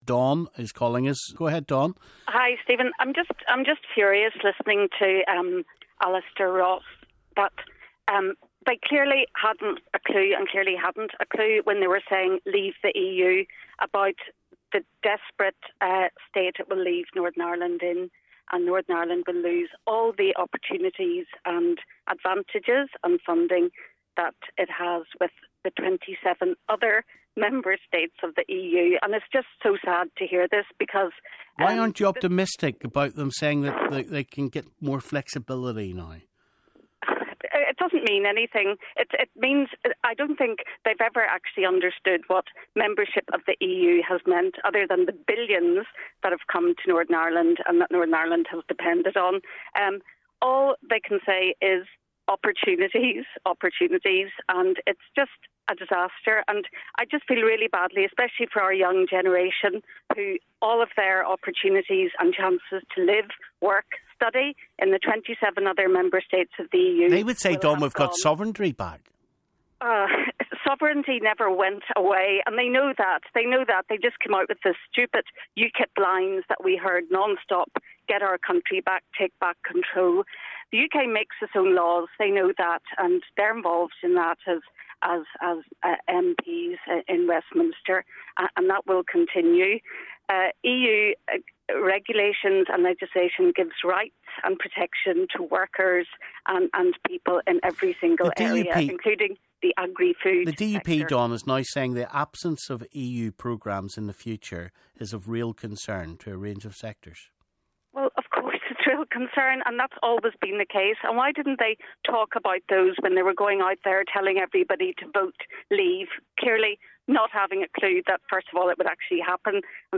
Brexit: Reaction from Nolan callers and commentators